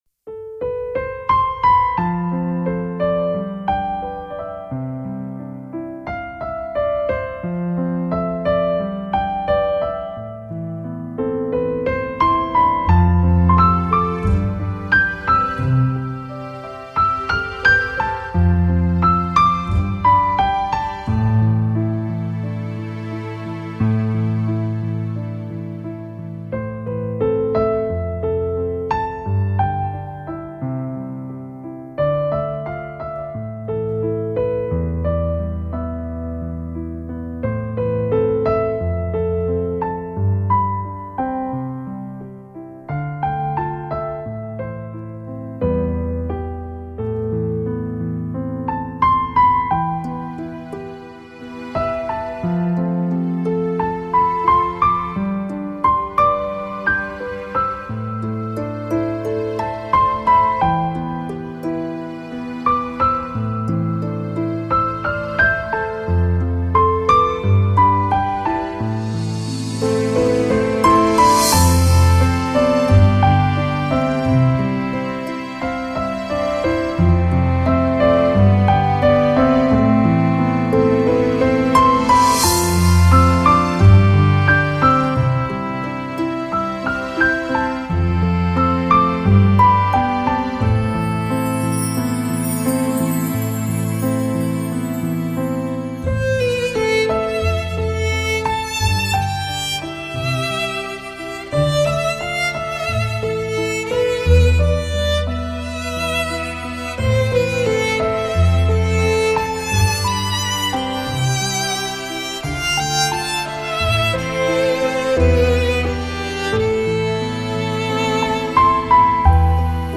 比较以往的曲风更清新明快，一些最具创新的钢琴，长笛，大提琴和小提琴组成的氛围。